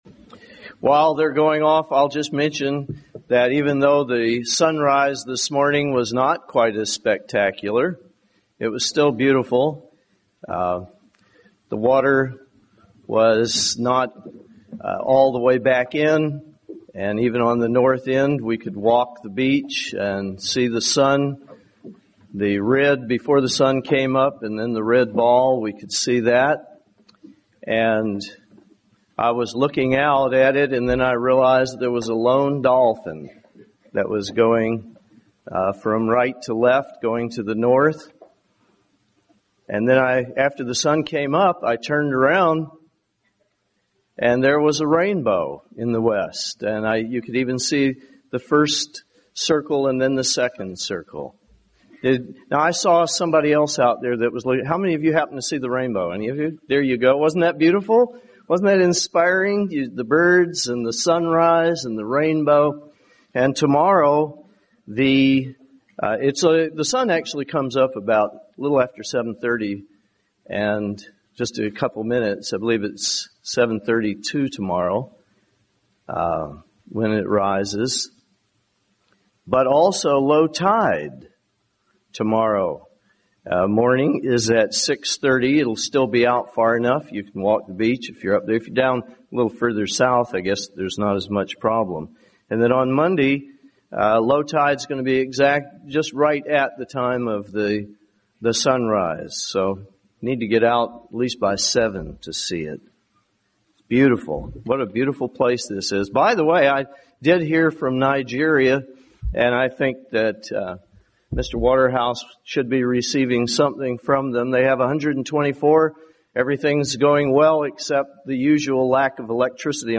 This sermon was given at the Jekyll Island, Georgia 2009 Feast site.